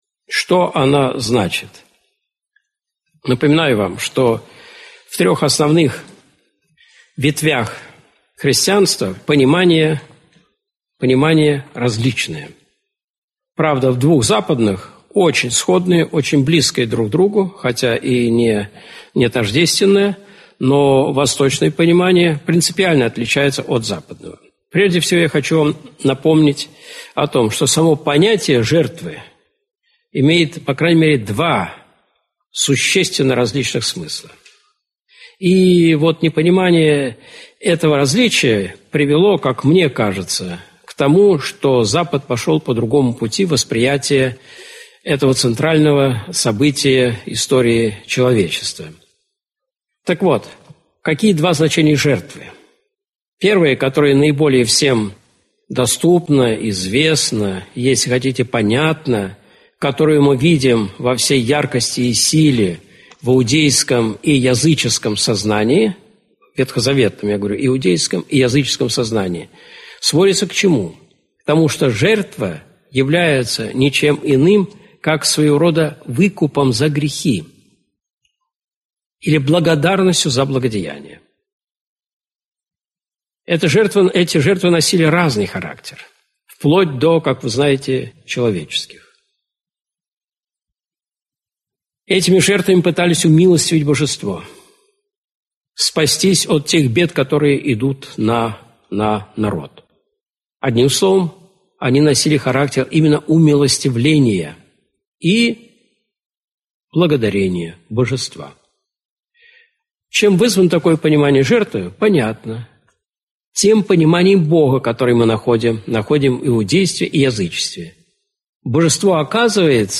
Аудиокнига Христос Спаситель. Смысл Жертвы Христовой | Библиотека аудиокниг